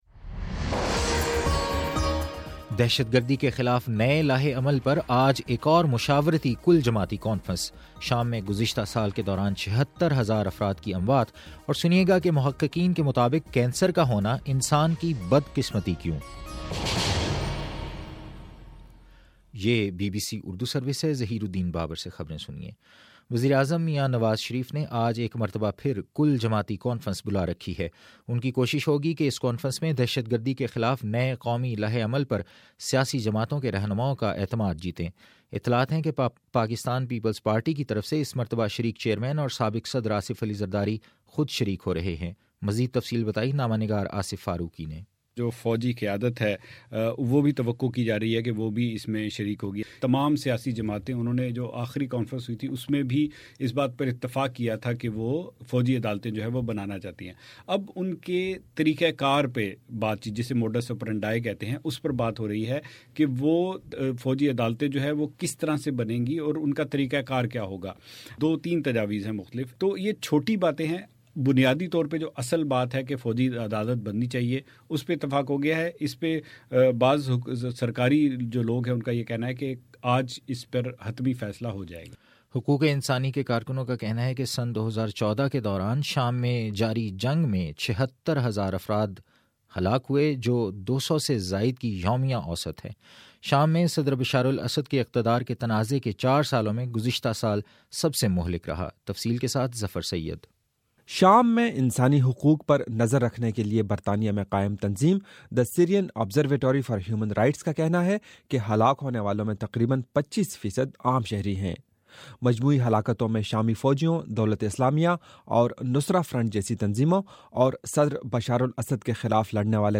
جنوری 02 : صبح نو بجے کا نیوز بُلیٹن